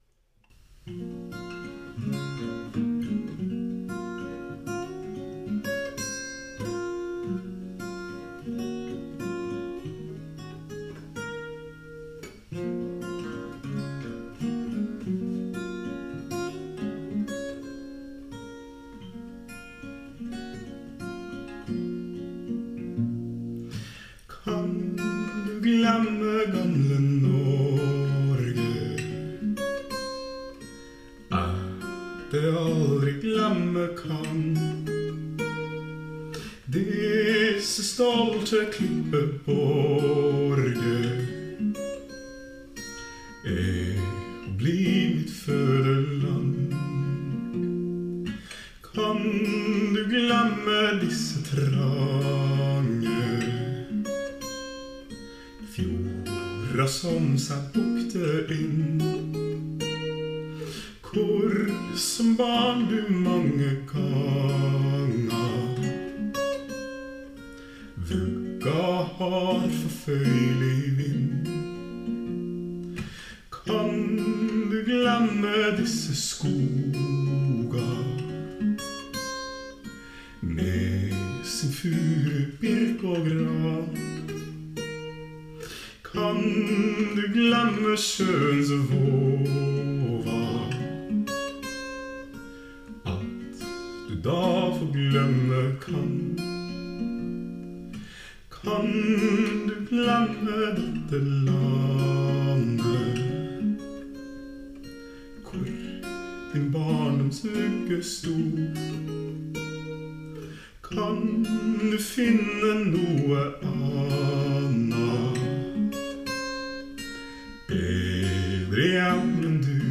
for arbeid med innstudering, fremføring, og innspilling, inkludert miksing og redigering